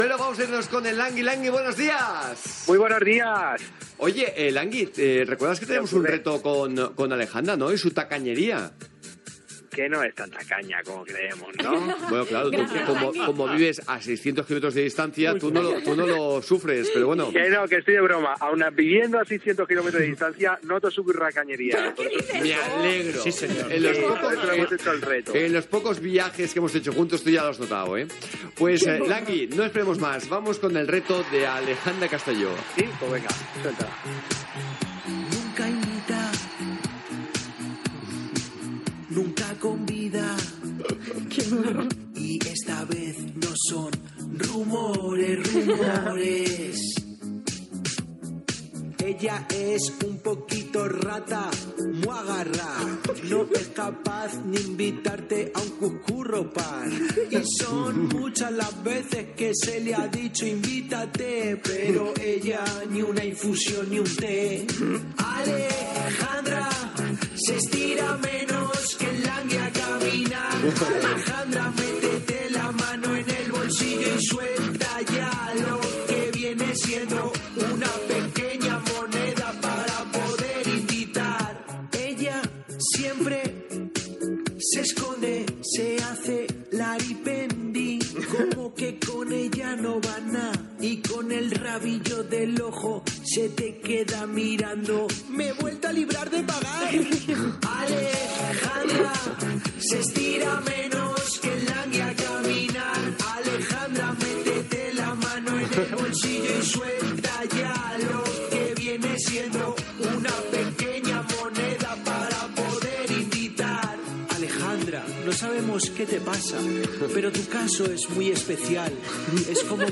El Langui (Juan Manuel Montilla) canta un rap a Alejandra Castelló sobre la seva garreperia. Comentaris de l'equip
Entreteniment